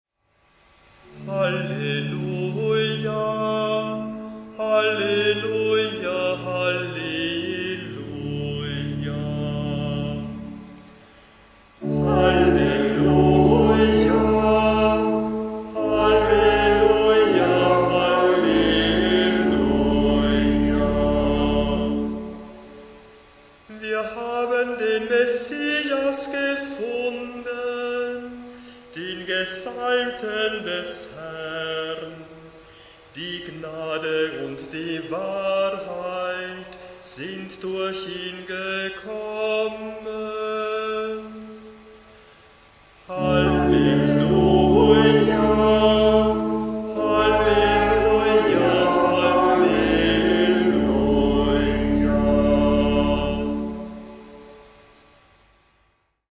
Ruf vor dem Evangelium 878 KB 2.
Orgel